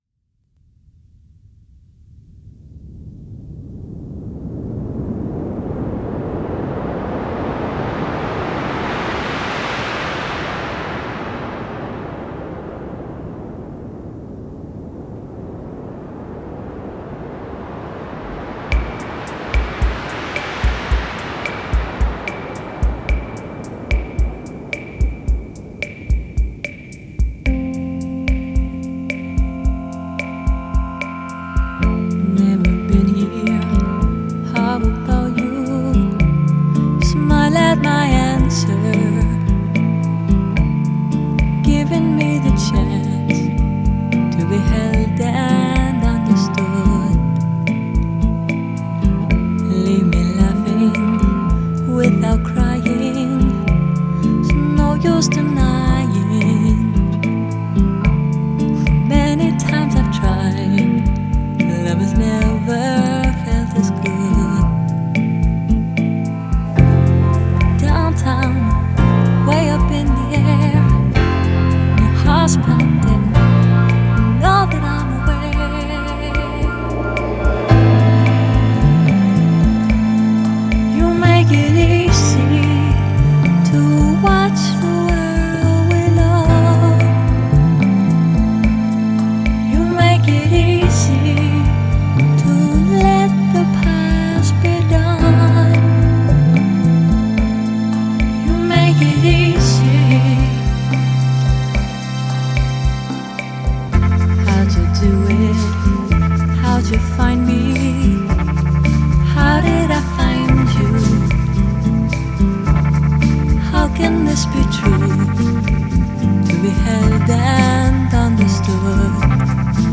Filed under blabla, electronica